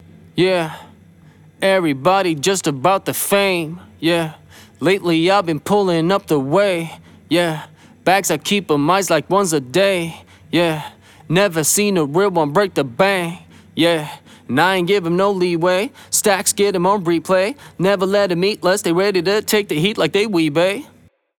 In the rap vocals below, a humming fridge in the studio made its way into the recording.
Singer before Vocal Cleanup
Rap_no_cleanup.mp3